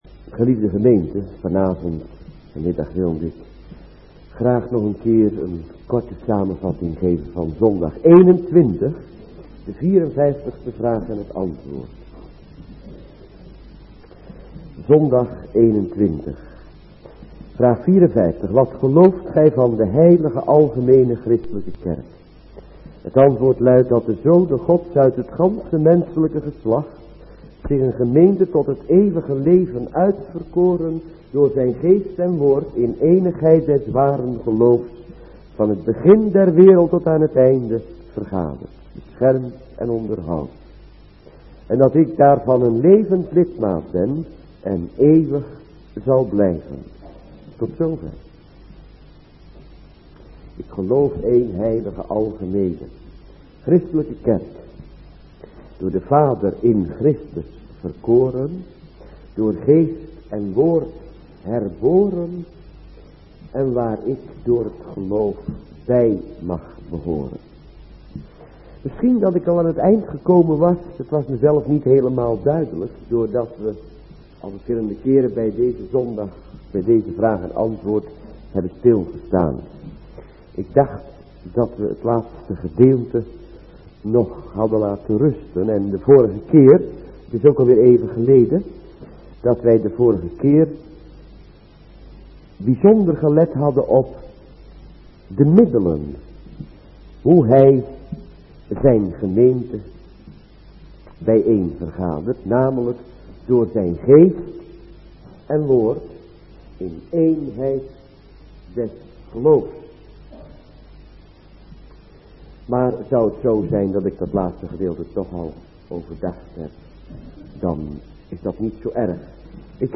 middagdienst